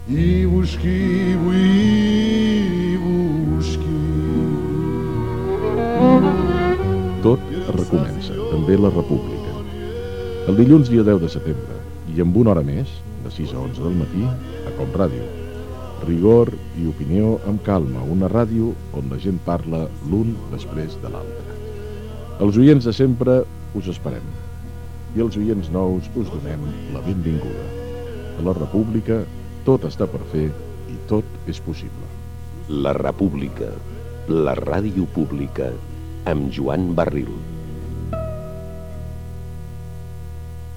Promoció del programa que retornava per començar la temporada 2001/2002